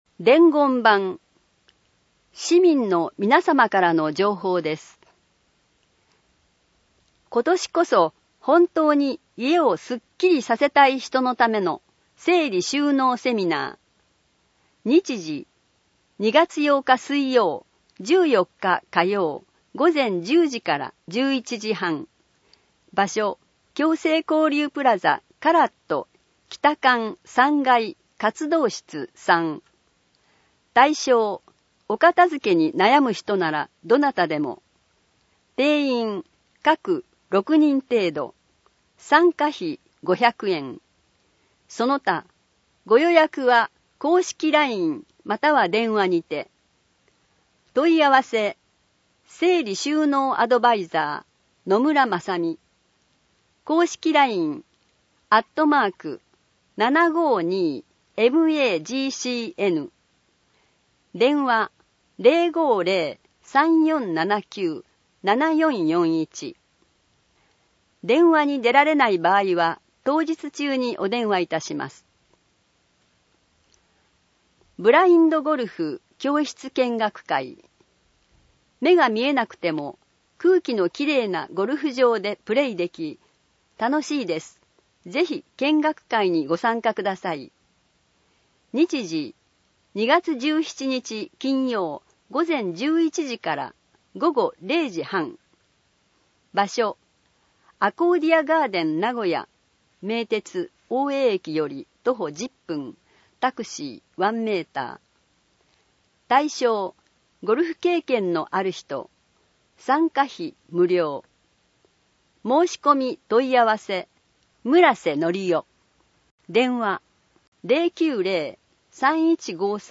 声の広報とよあけは、視覚障がい者のみなさんを対象に、種々の情報提供を行っている草笛の会のご協力で、市からのお知らせなどが掲載された広報紙などを音訳していただき、音声データとして提供いたします。